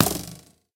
bowhit2.ogg